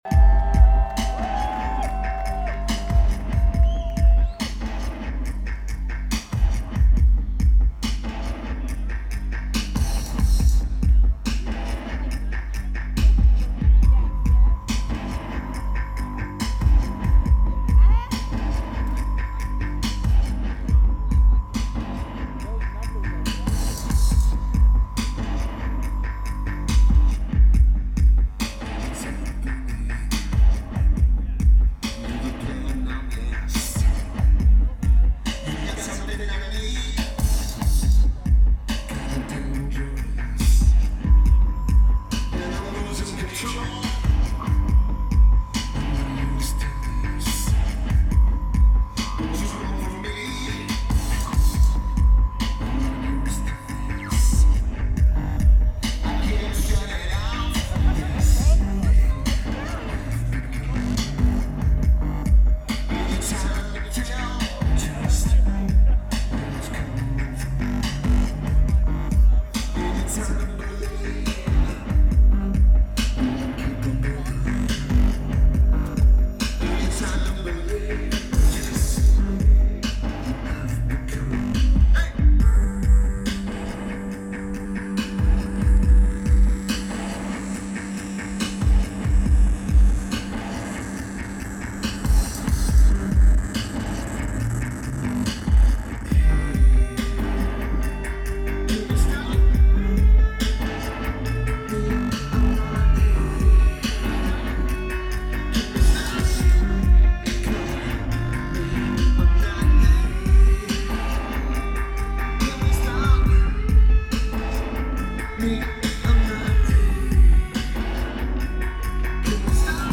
Boston Calling
Lineage: Audio - AUD (Sony PCM-A10)